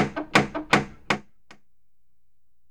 doornokr.wav